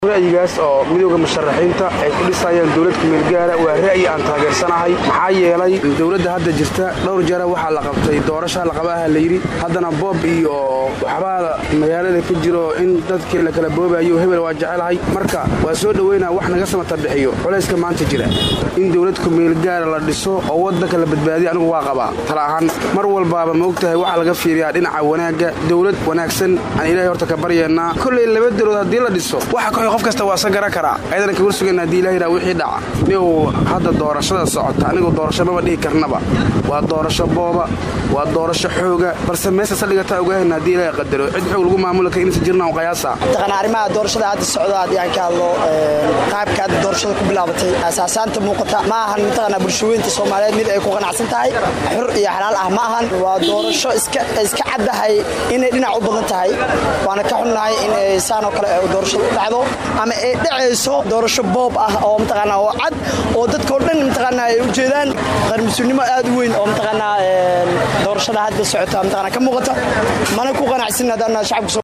DHAGEYSO:Shacabka Soomaaliyeed oo ka hadlay go’aamo ay soo saareen musharraxiinta Soomaaliya